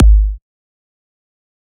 EDM Kick 4.wav